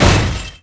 m_drop_large_axe_01.wav